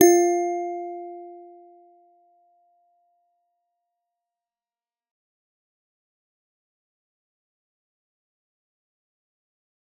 G_Musicbox-F4-f.wav